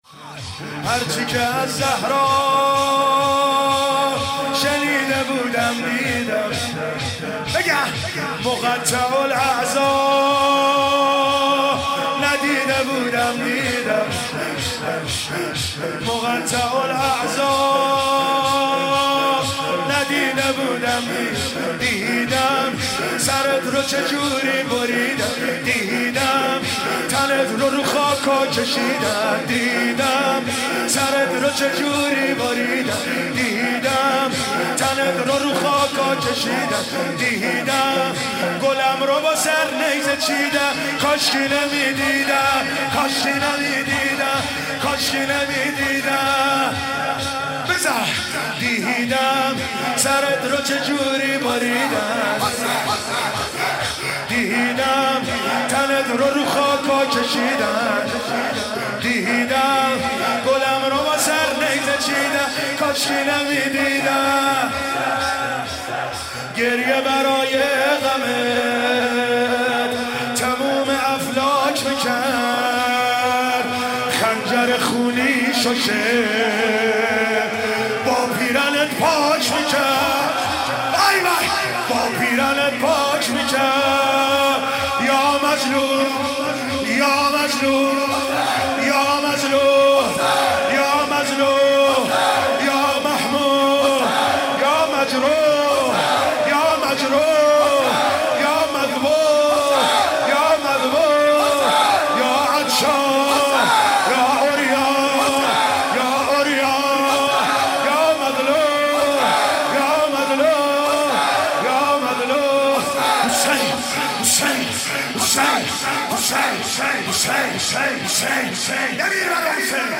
شب نهم محرم _شور_هر چی که از زهرا شنیده بود دیدم
مداحی